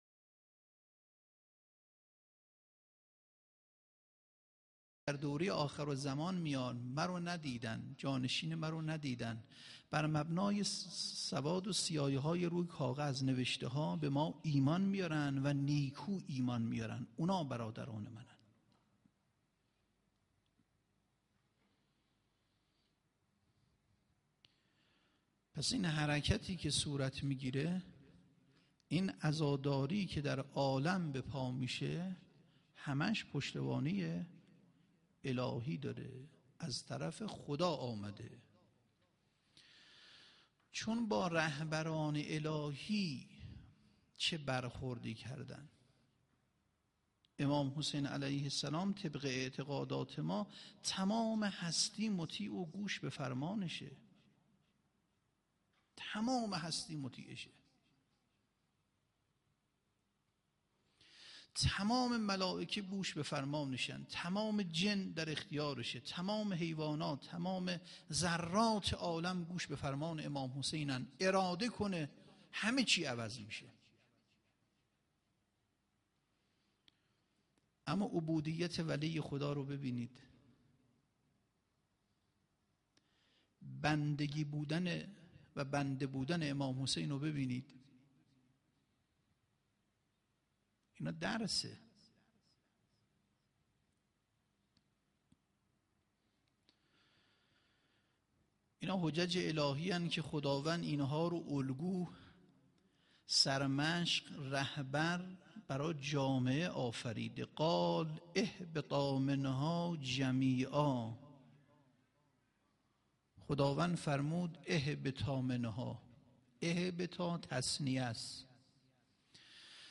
سخنرانی3.mp3